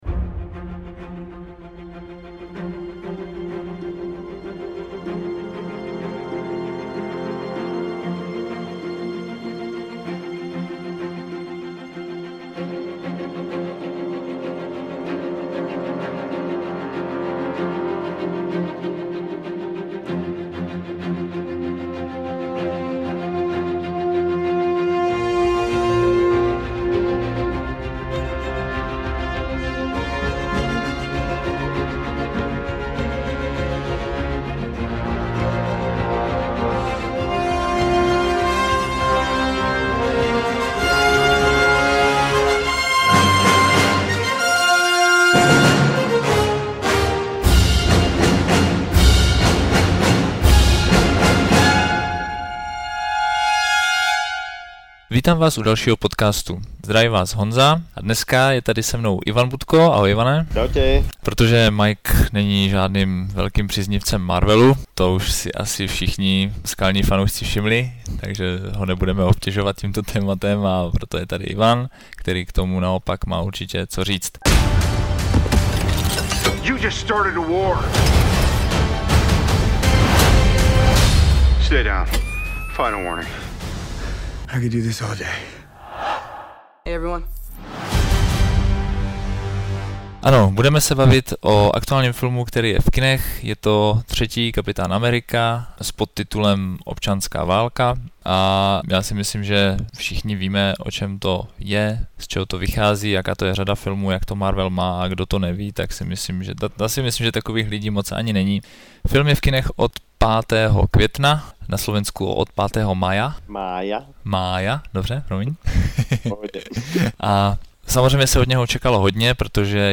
Debatující